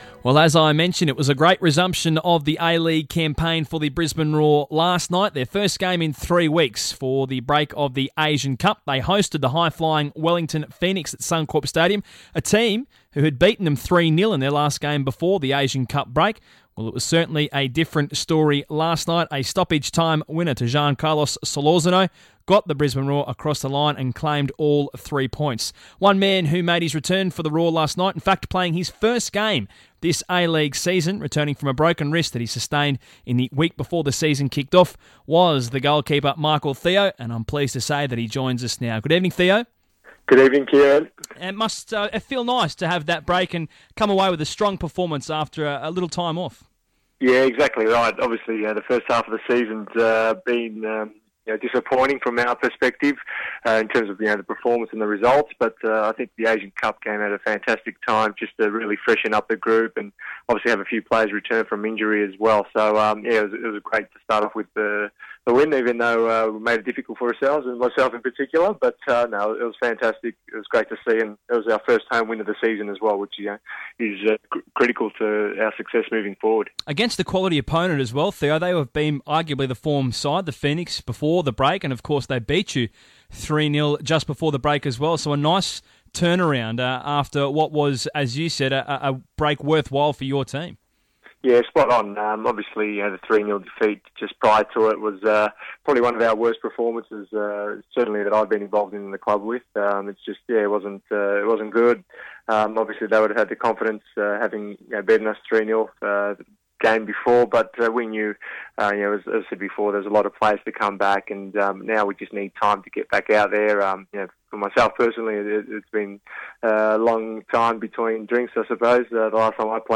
The Brisbane Roar resumed their A-league campaign on Monday night with a hard fought 3-2 victory over the Wellington Phoenix. Goalkeeper Michael Theo joins Sports Today to discuss the win and the road ahead.